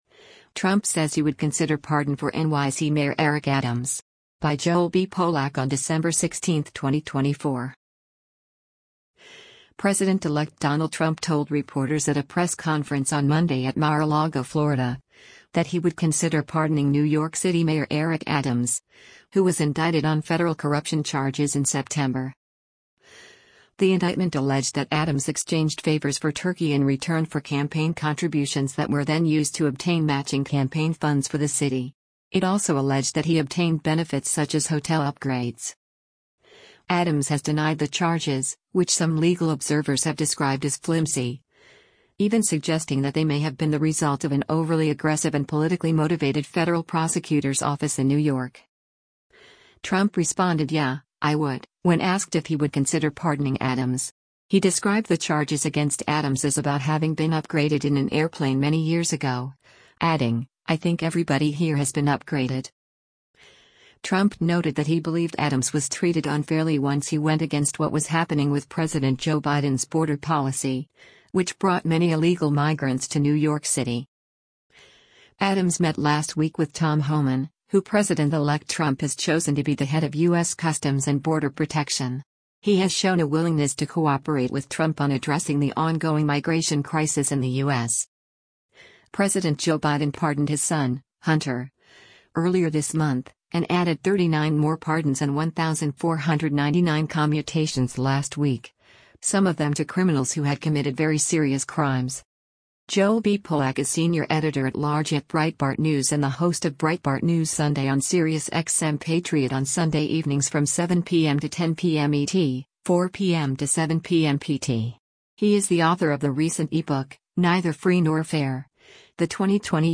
President-elect Donald Trump told reporters at a press conference on Monday at Mar-a-Lago, Florida, that he would consider pardoning New York City Mayor Eric Adams, who was indicted on federal corruption charges in September.